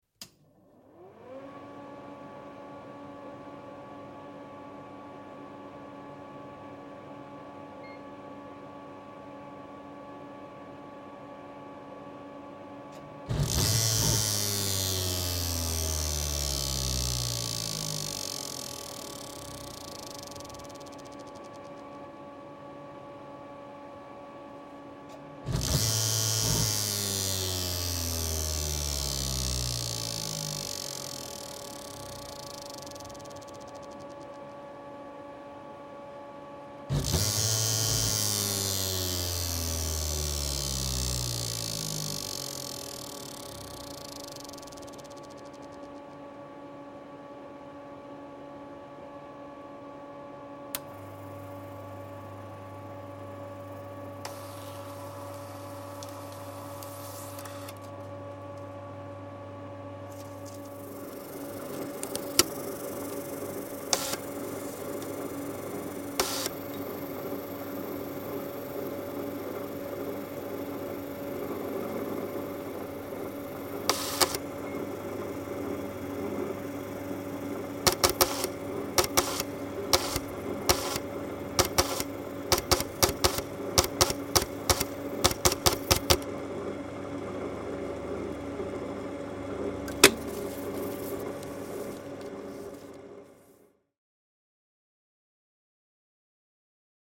Boot sequence and a repeated mounting of tape reader tape spool followed by processing of paper tape data message. Year of manufacture: 1965